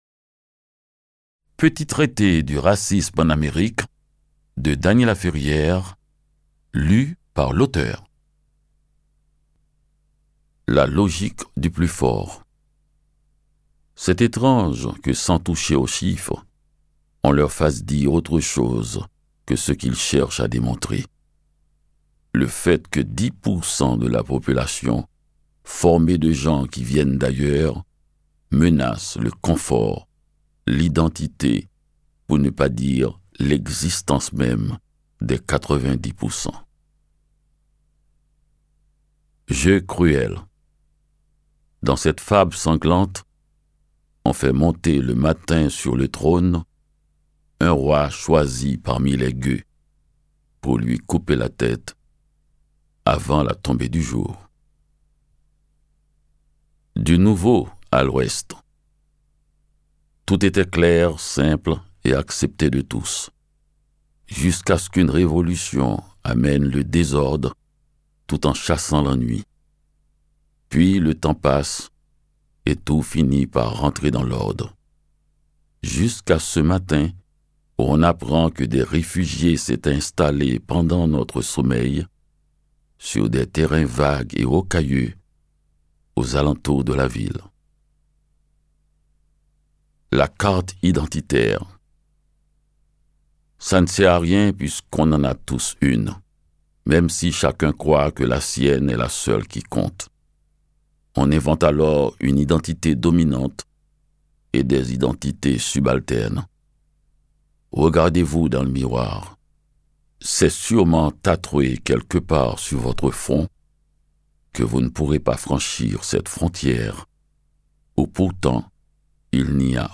Lire un extrait - Petit traité du racisme en Amérique de Dany Laferrière
La lecture saisissante de l’auteur invite à une réflexion profonde. Voici donc un livre de réflexion et de tact, un livre littéraire.